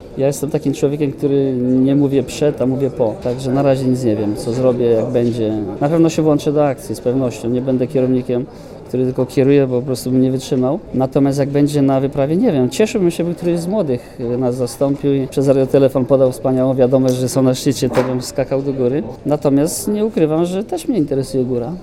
Posłuchaj mówi Krzysztof Wielicki